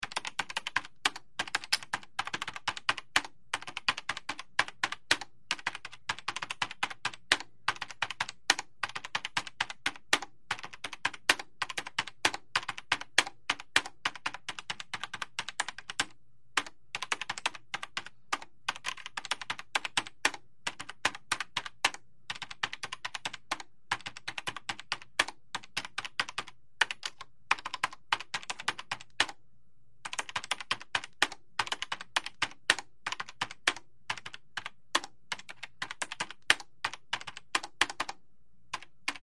Mac Keyboard 4; Desktop Keyboard; Numeric Keypad Entry, Close Perspective.